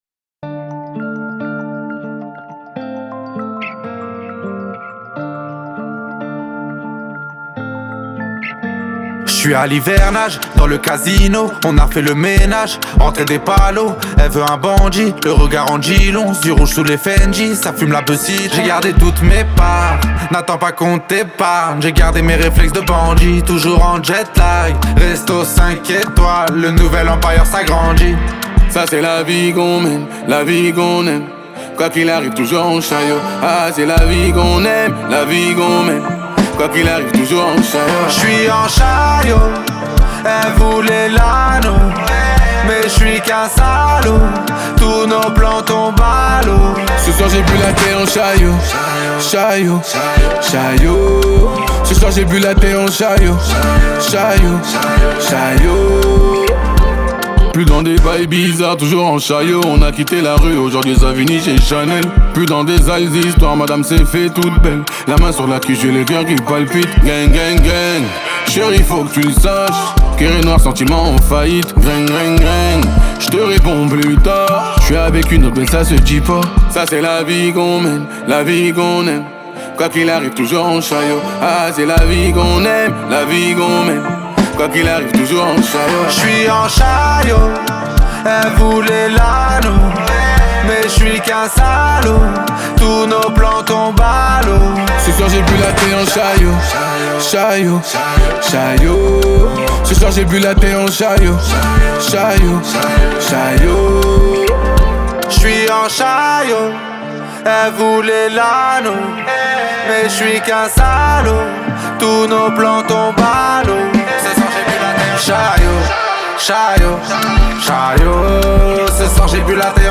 8/100 Genres : raï Écouter sur Spotify